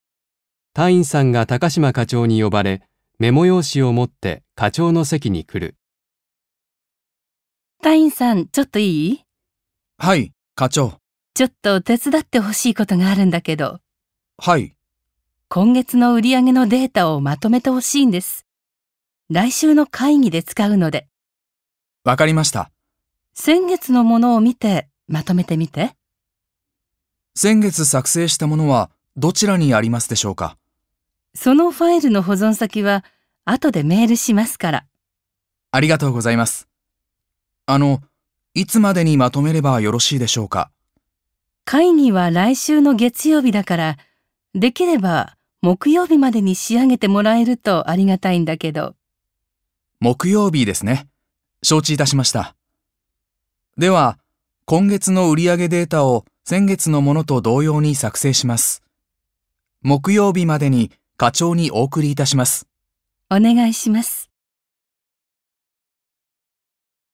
1. 会話 （指示しじを受ける）